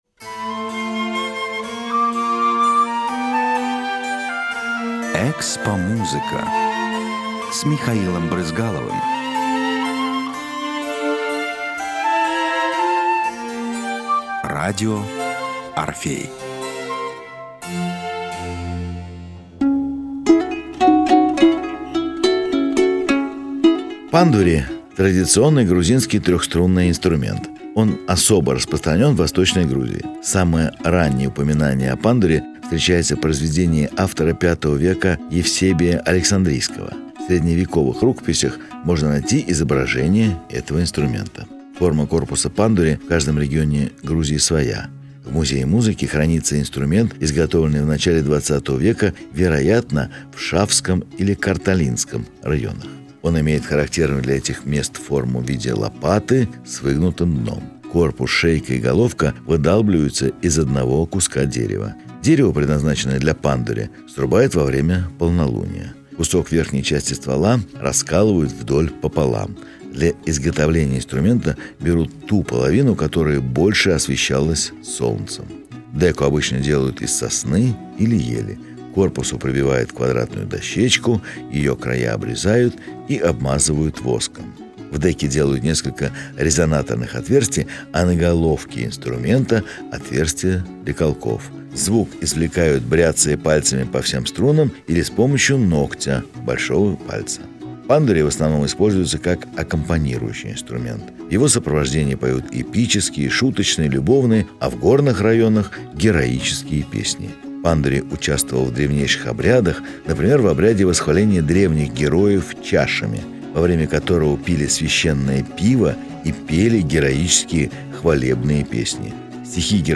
Пандури – традиционный грузинский трёхструнный инструмент.
Звук извлекают, бряцая пальцами по всем струнам или с помощью ногтя большого пальца.
Пандури в основном используется как аккомпанирующий инструмент.
Экспомузыка-Пандури.mp3